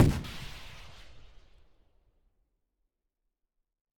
rpg_far.ogg